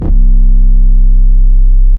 Droid808_YC.wav